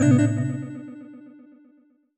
jingle_chime_16_negative.wav